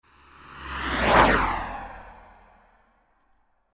دانلود آهنگ مسابقه 2 از افکت صوتی حمل و نقل
جلوه های صوتی
برچسب: دانلود آهنگ های افکت صوتی حمل و نقل دانلود آلبوم صدای مسابقه ماشین از افکت صوتی حمل و نقل